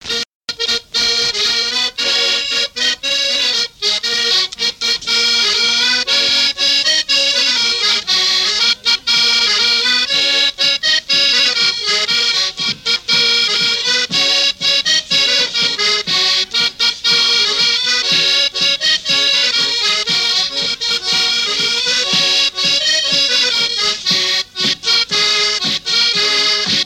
Chants brefs - A danser
danse-jeu : guimbarde
Pièce musicale inédite